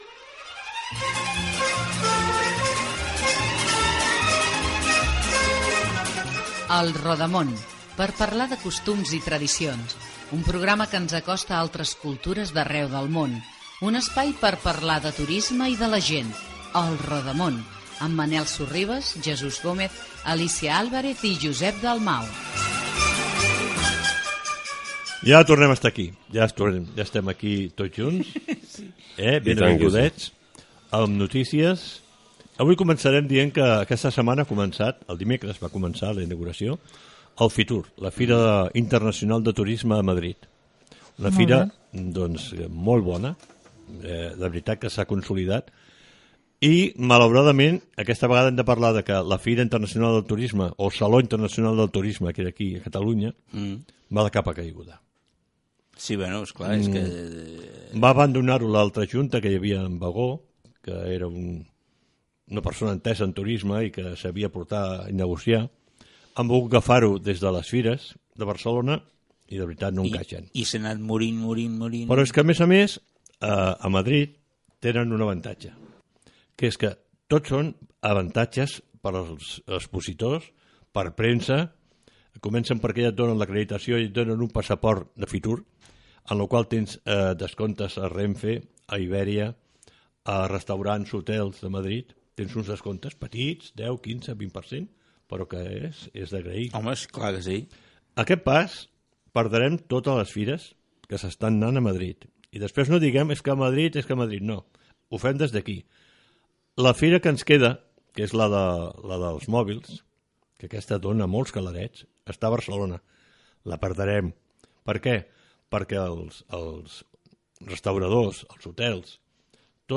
Careta, presentació, comentari sobre la celebració de la fira Fitur Gènere radiofònic Entreteniment